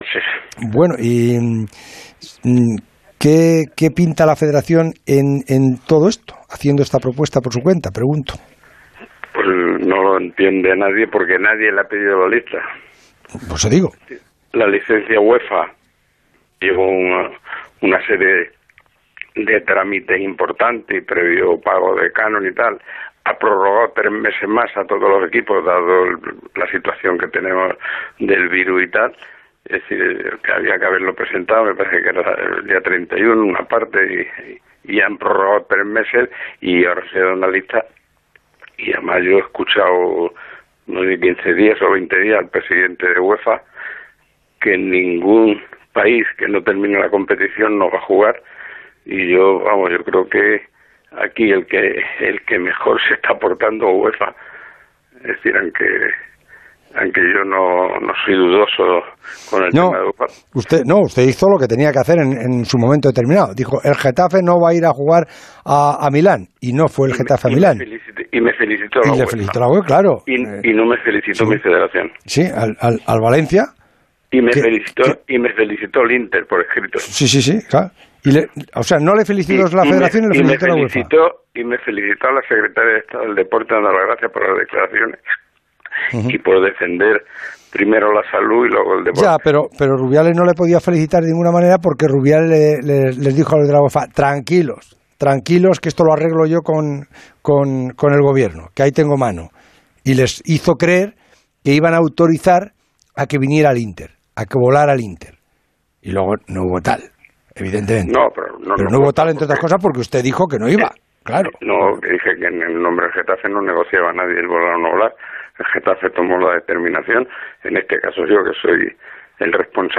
pasó por los micrófonos de El Transistor de Onda Cero: